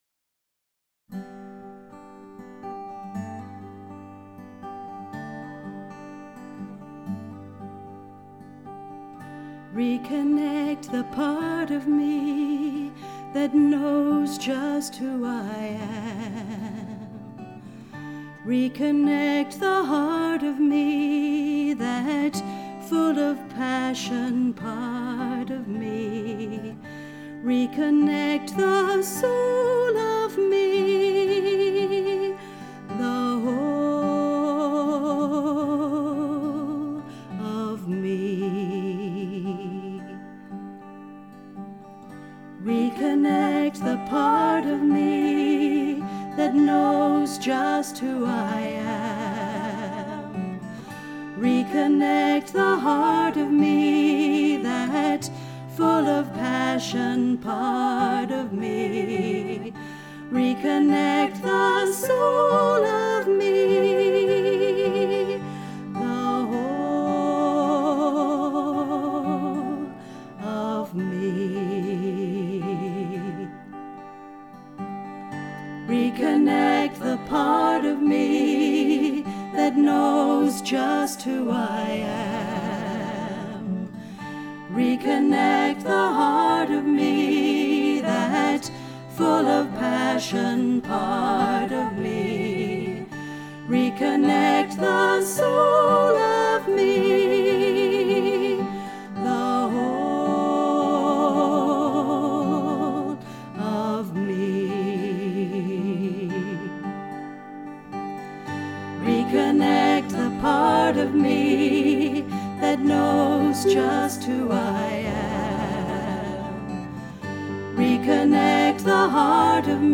soprano
alto
piano